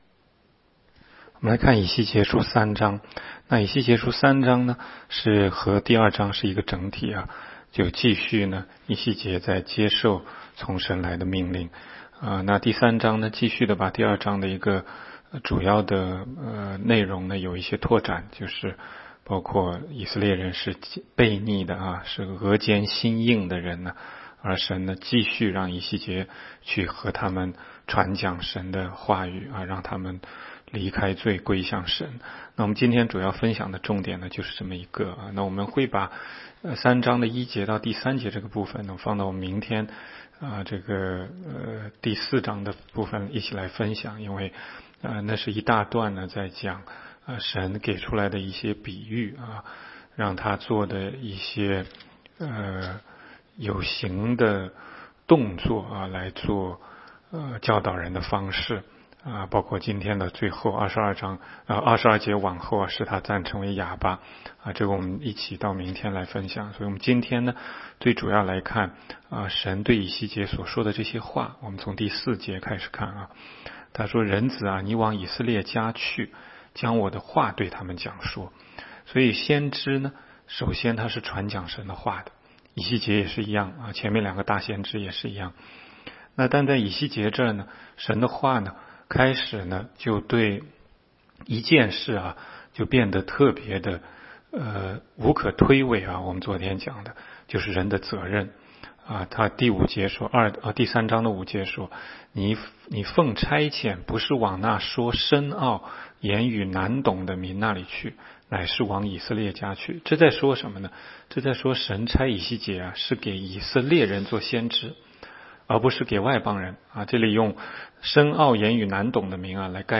16街讲道录音 - 每日读经 -《以西结书》3章